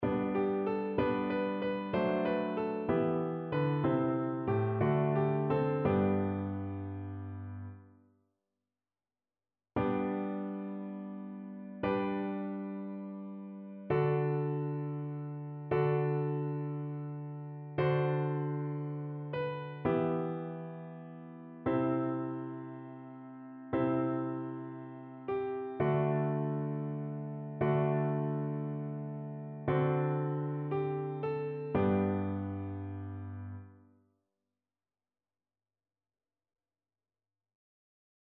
Chœur
annee-abc-fetes-et-solennites-dedicace-des-eglises-psaume-83-satb.mp3